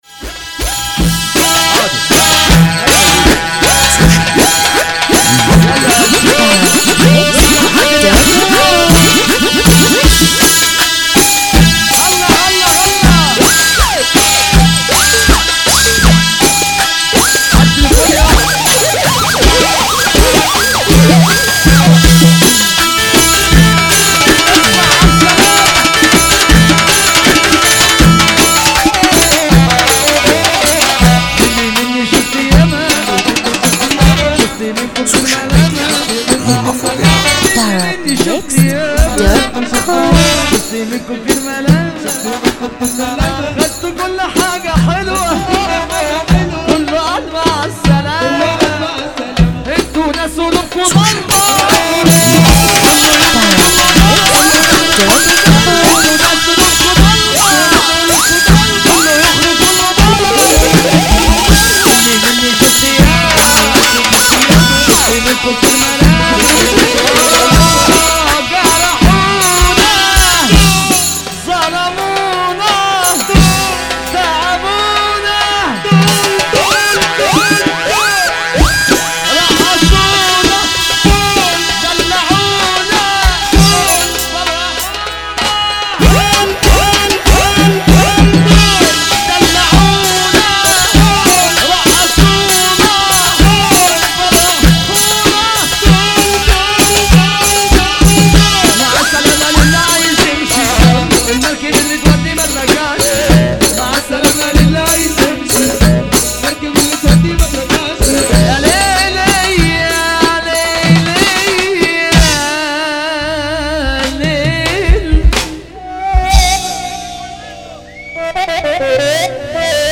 موال
حزينة جدا